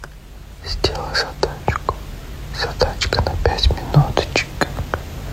zadachka na 5 minut Meme Sound Effect
Category: Reactions Soundboard